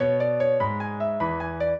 piano
minuet4-5.wav